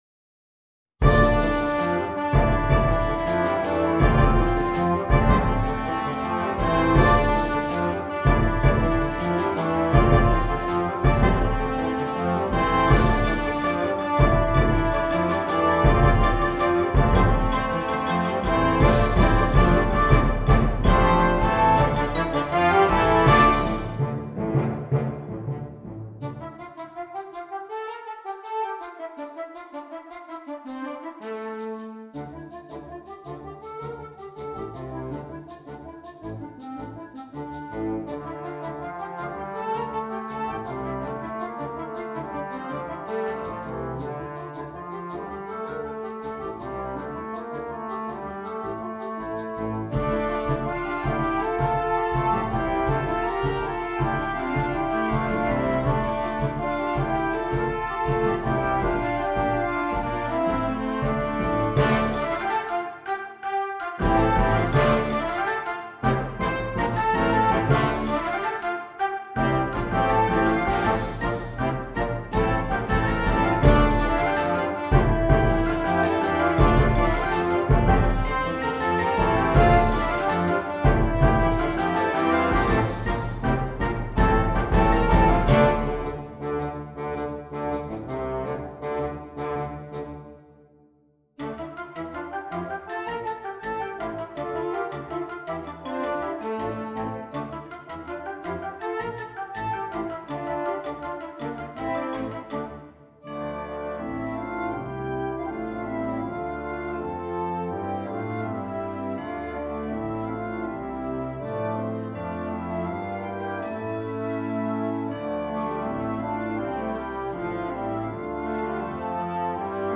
Voicing: Flex Band